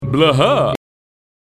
PLAY Drac React (Fright Laugh)
drac-laugh-react-2_dd6wQzf.mp3